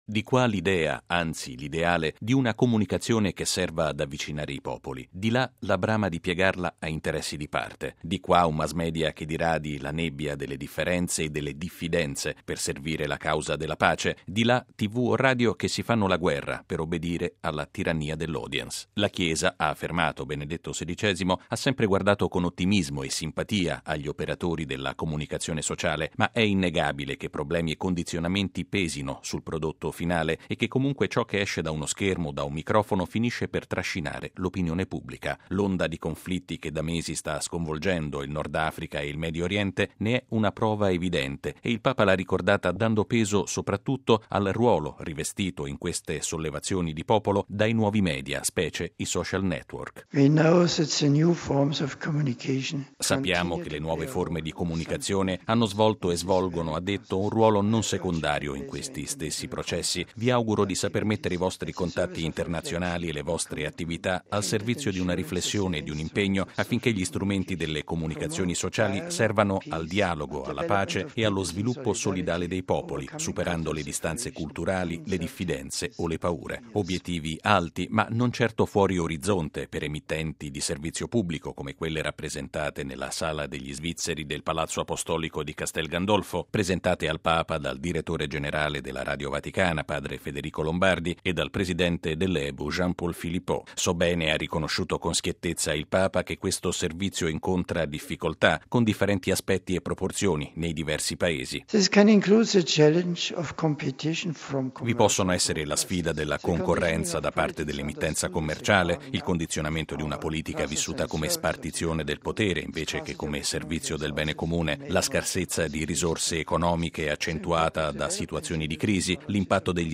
L’auspicio è stato espresso questa mattina da Benedetto XVI durante l’udienza concessa in Vaticano ai circa 150 partecipanti all'Assemblea delle Radio dell’“European Broadcasting Union” (Ebu), organizzata nei giorni scorsi dalla Radio Vaticana in occasione del suo 80.mo di fondazione.